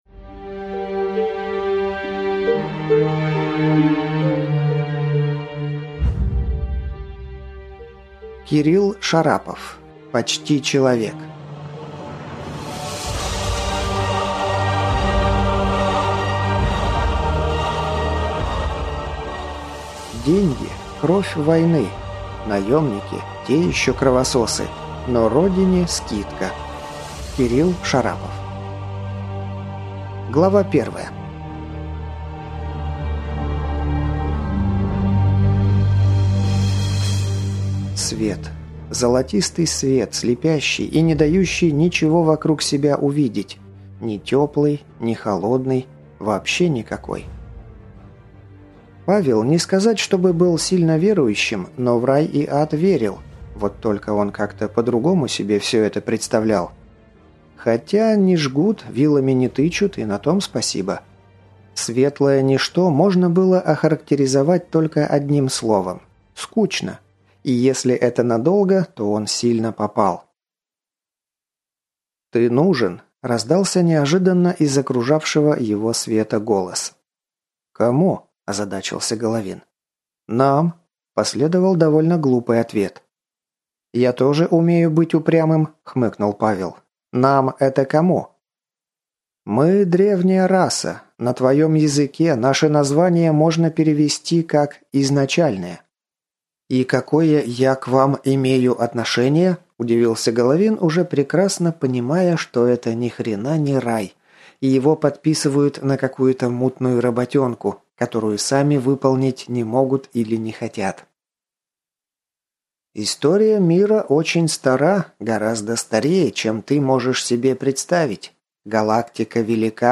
Аудиокнига Почти человек | Библиотека аудиокниг